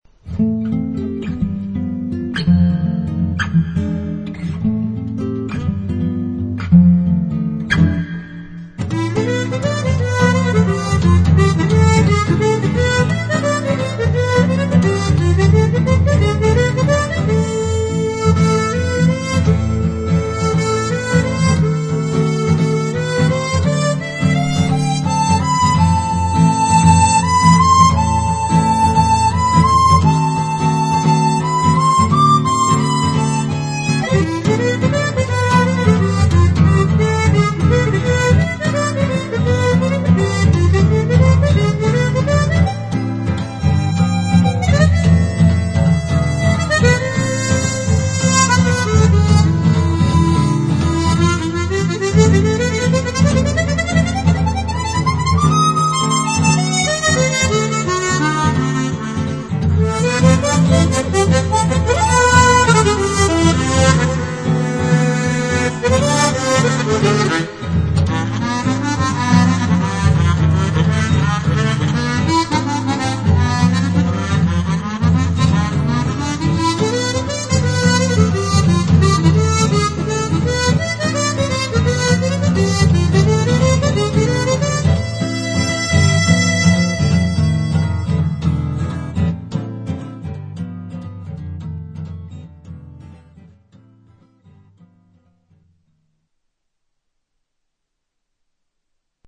トレモロクロマチックハーモニカは、名前の通りトレモロ音で演奏できるクロマチックハーモニカです。